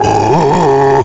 Chunky Kong's Tarzan yell
Chunky's_Tarzan_Yell.oga.mp3